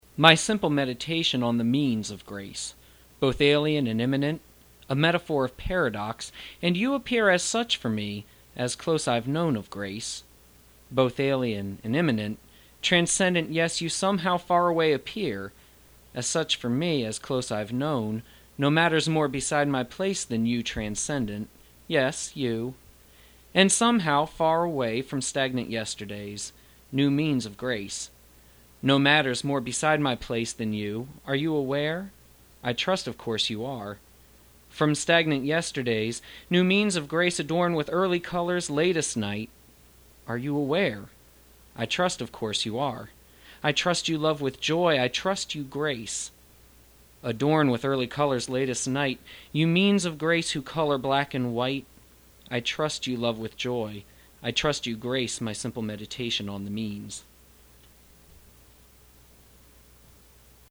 recite his poetry